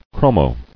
[chro·mo]